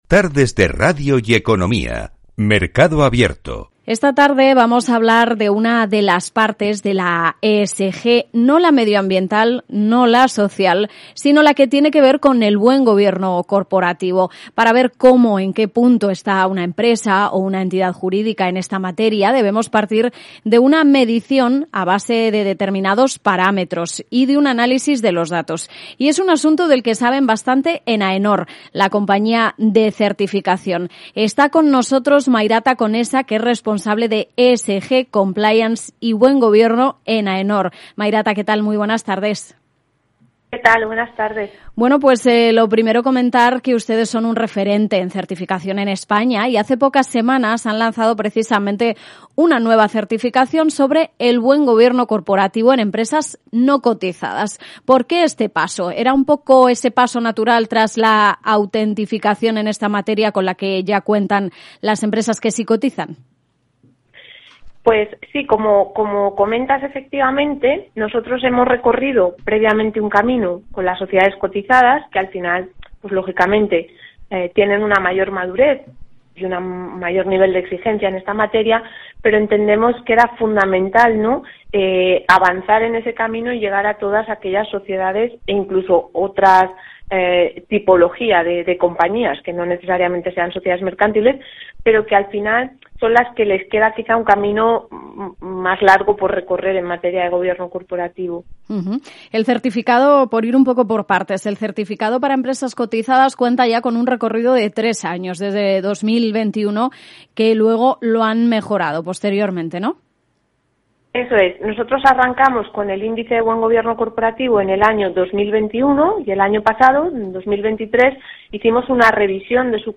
Entrevista | AENOR lanza una nueva certificación sobre Buen Gobierno en empresas no cotizadas.